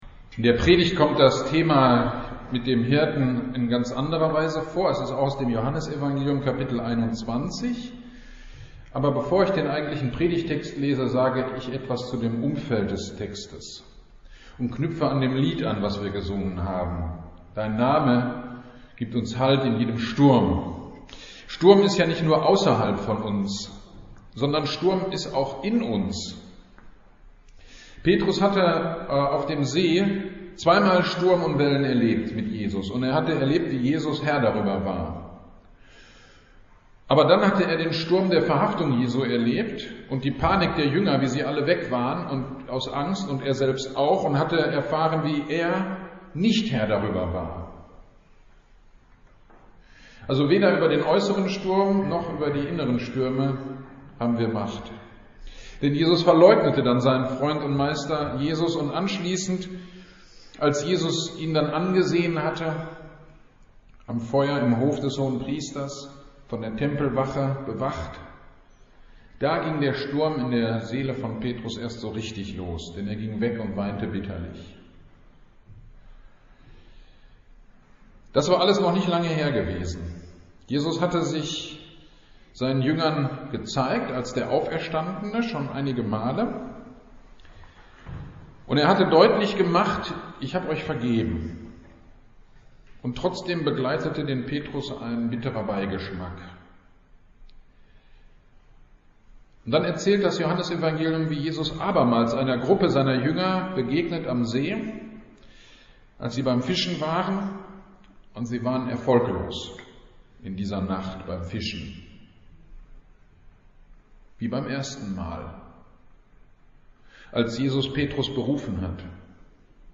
GD am 01.05.22 Predigt zu Johannes 21.15-19